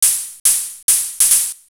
HIHAT029_TEKNO_140_X_SC2.wav